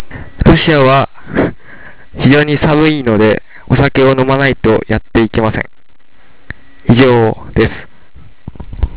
研修生の声　４